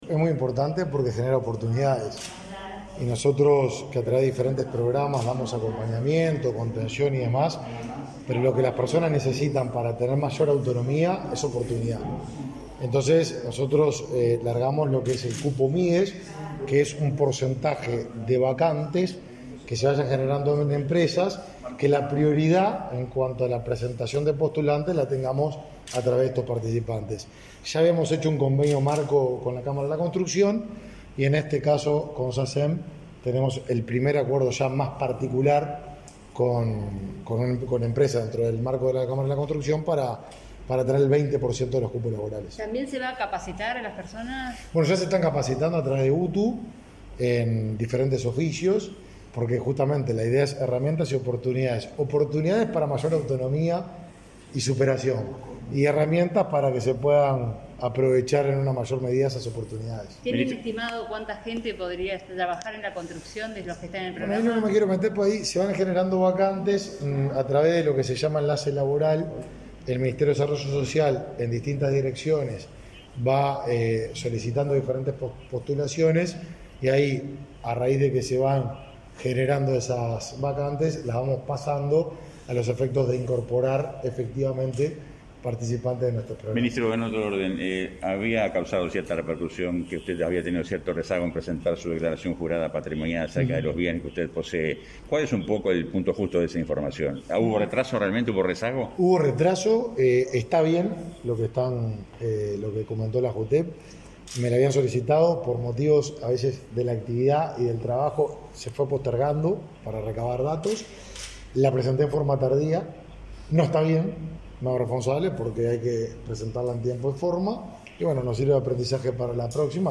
Declaraciones a la prensa del ministro Martín Lema
Luego, dialogó con la prensa.